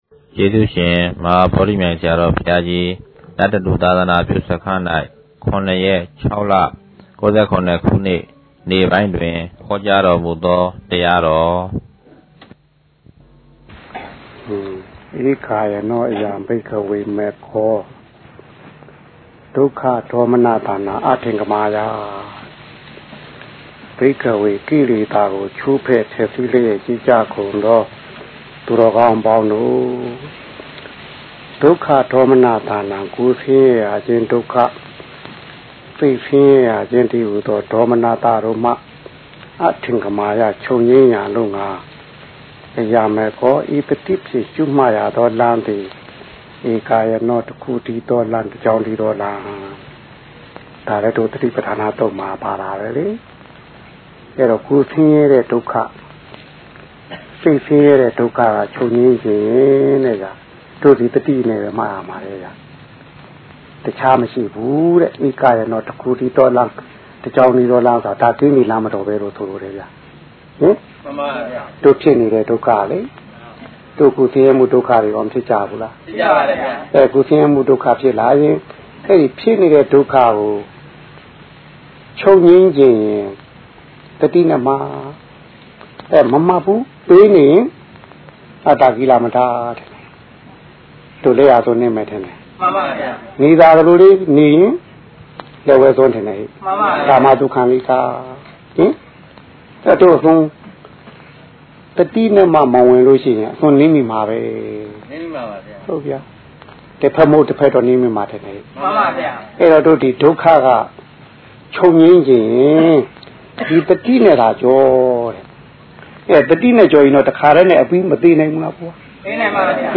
အခွေ(၁) ၀ိပဿနာအလုပ်ပေးတရားတော်များ